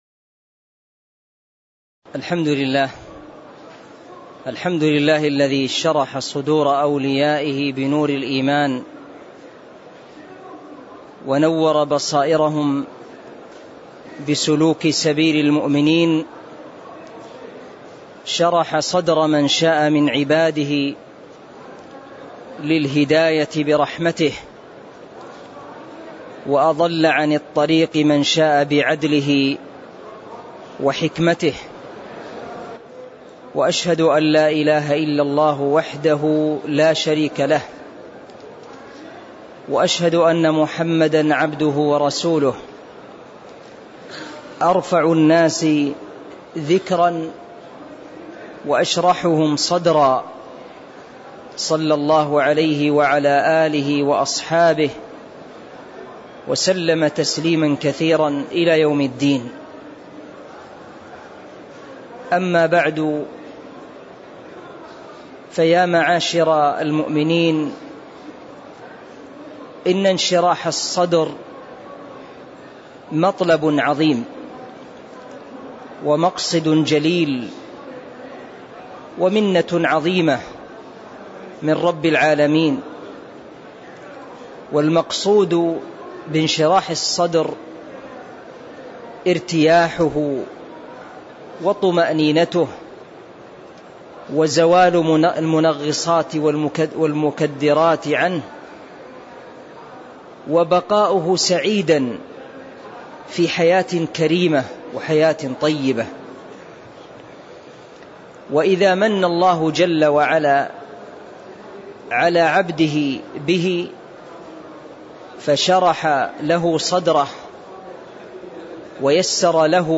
تاريخ النشر ٢٧ ذو القعدة ١٤٤٣ هـ المكان: المسجد النبوي الشيخ